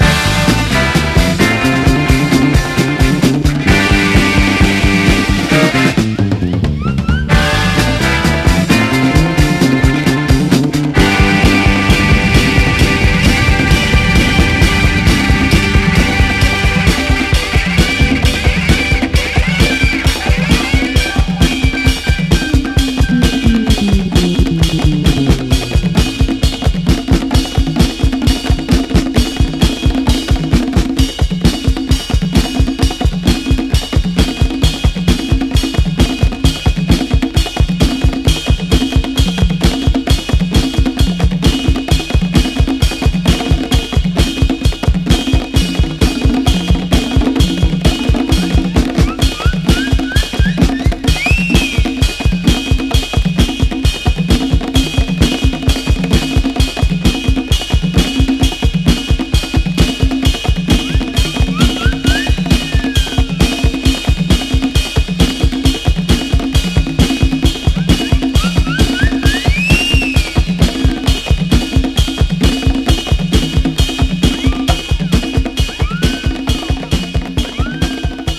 SOUL / RARE GROOVE/FUNK
囚人ファンク！パーカッションが転げまわるB-BOYブレイクからエレピ入りメロウ・ファンクまで最高！
パーカッションが沸き立つB-BOYブレイク入りのキラー・インスト・ファンク
エレピがきらめくメロウ・ファンク